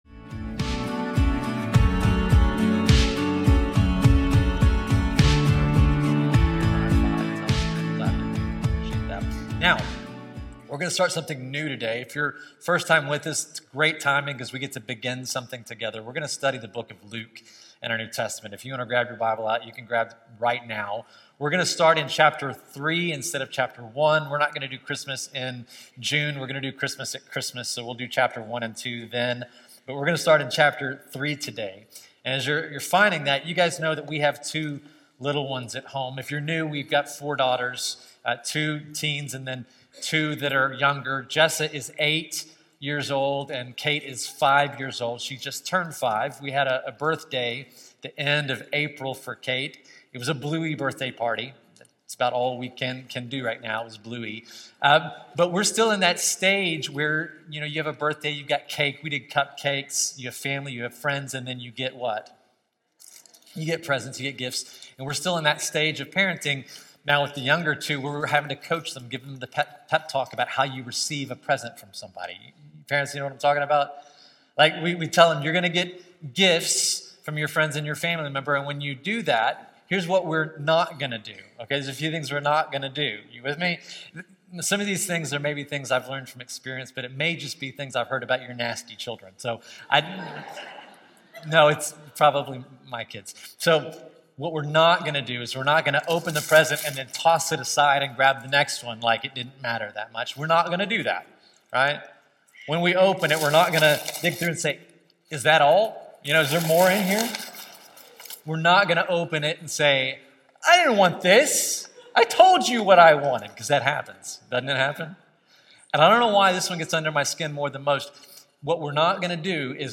This sermon explores three major movements of the passage: